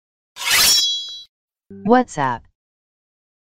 Nada Dering WA suara Pedang Samurai
Suara unik ini memberikan kesan tajam dan keren setiap ada pesan masuk.
nada-dering-wa-suara-pedang-samurai-id-www_tiengdong_com.mp3